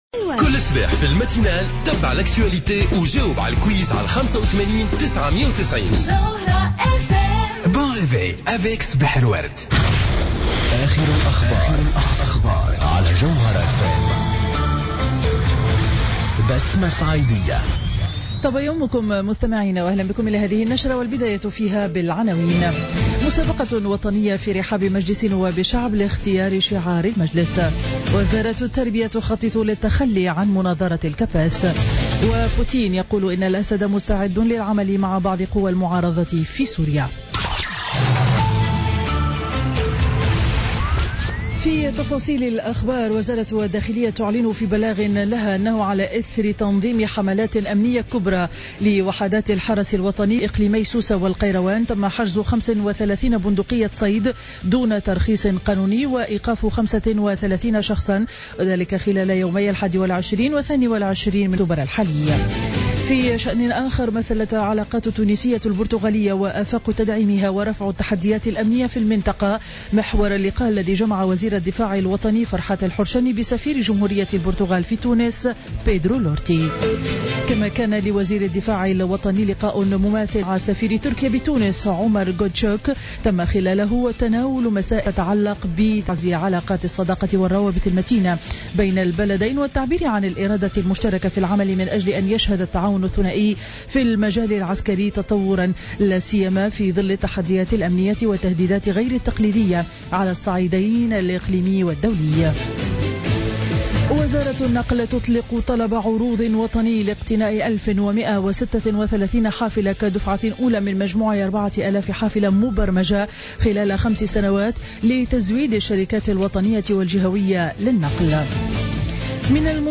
نشرة أخبار السابعة صباحا ليوم الجمعة 23 أكتوبر 2015